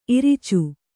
♪ iricu